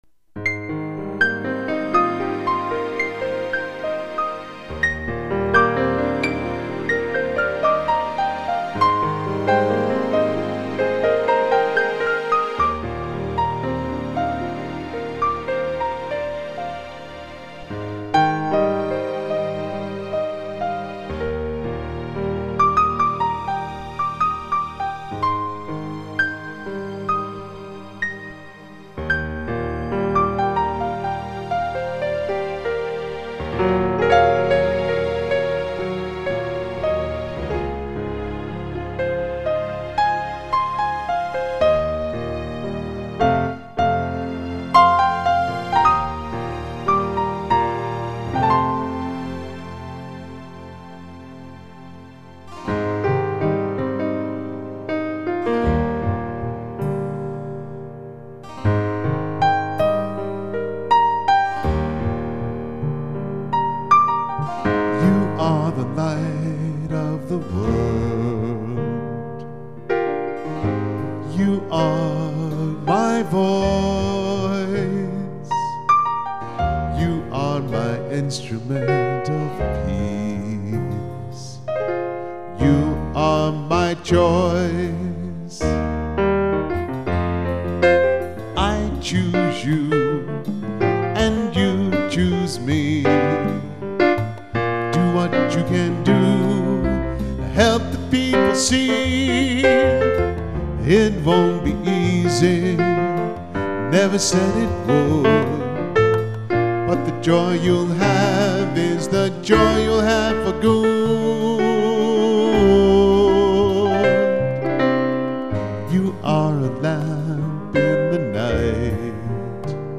Contemporary Christian music
Keyboard/Vocals